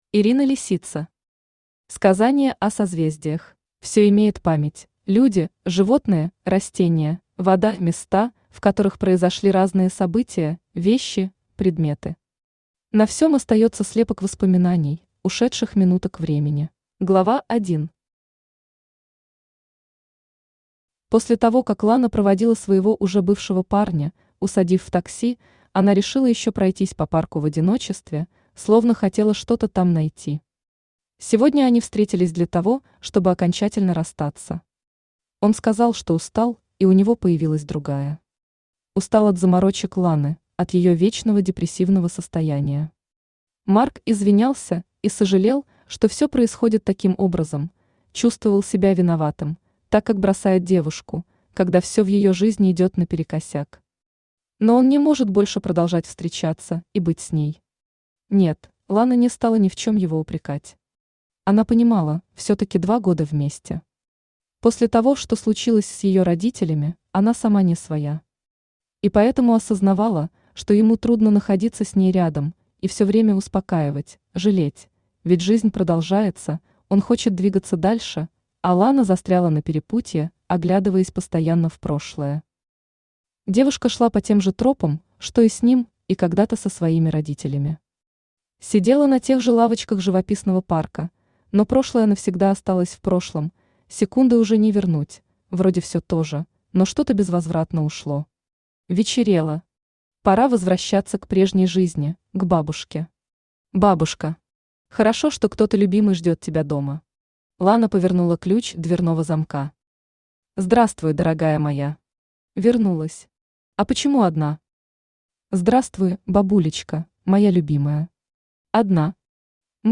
Аудиокнига Сказание о Созвездиях | Библиотека аудиокниг
Aудиокнига Сказание о Созвездиях Автор Ирина Лисица Читает аудиокнигу Авточтец ЛитРес.